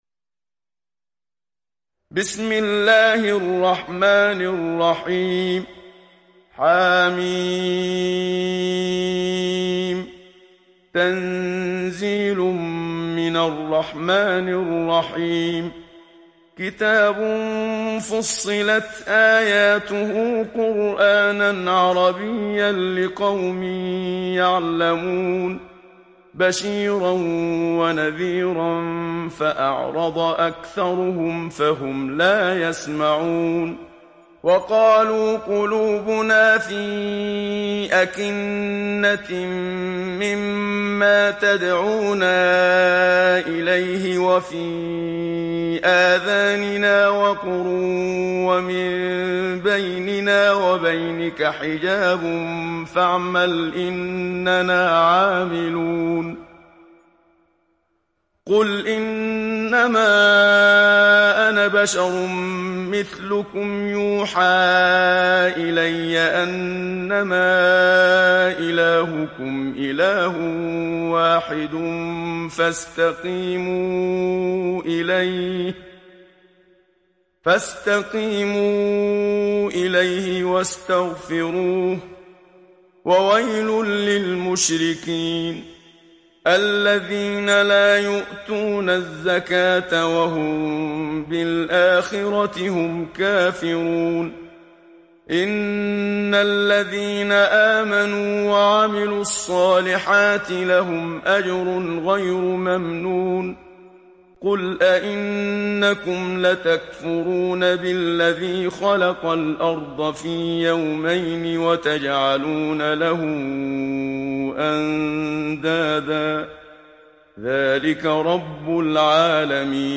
دانلود سوره فصلت mp3 محمد صديق المنشاوي روایت حفص از عاصم, قرآن را دانلود کنید و گوش کن mp3 ، لینک مستقیم کامل